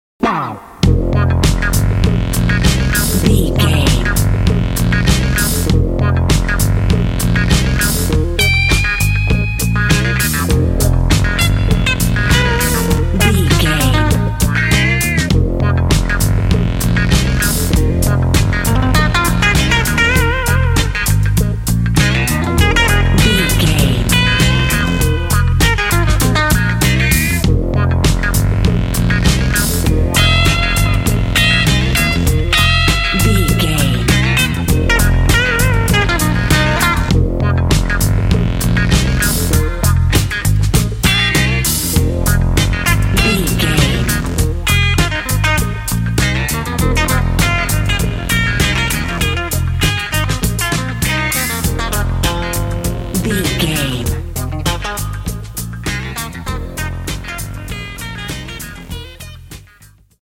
This funky track is full of rhythm and cool guitar leaks.
Aeolian/Minor
funky
bouncy
groovy
drum machine
electric guitar
synthesiser
bass guitar